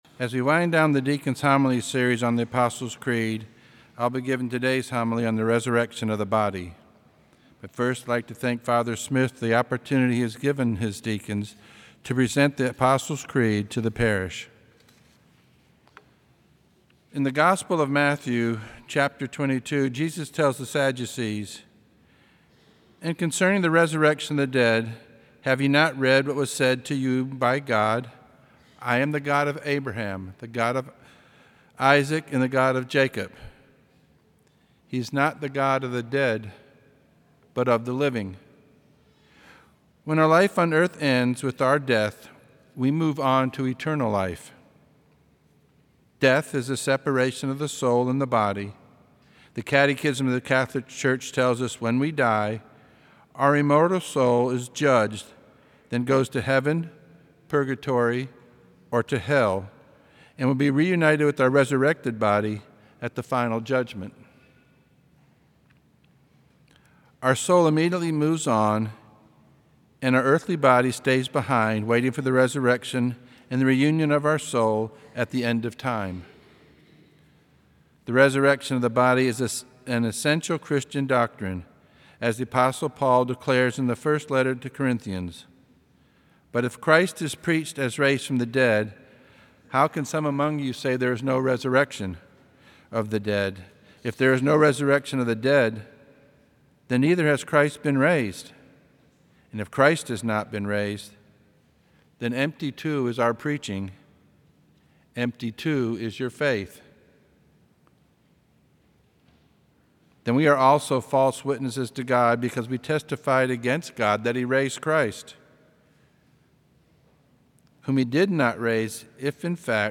From Series: "Homilies"
Homilies that are not part of any particular series.